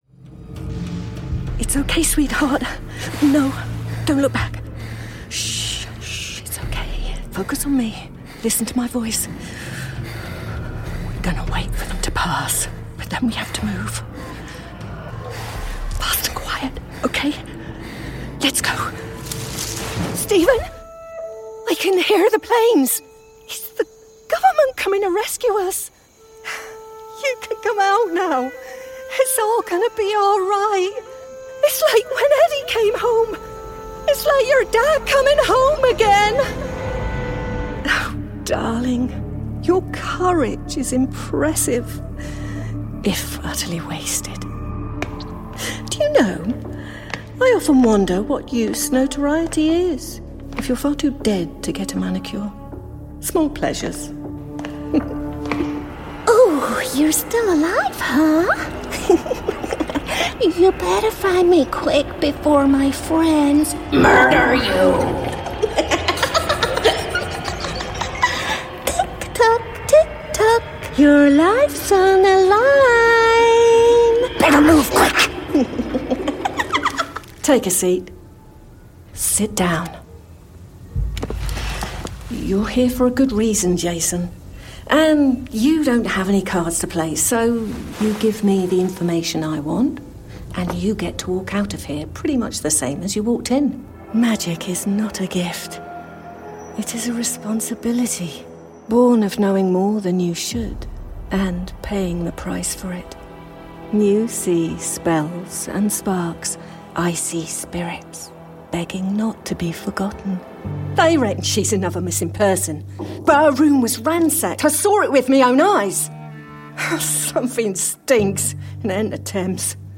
Videojuegos
Mi voz suele describirse como confiable, juguetona, inteligente y amigable.
Espacio de grabación: cabina de sesiones de la serie DW
micrófono: AKG C414 XL11
Mezzosoprano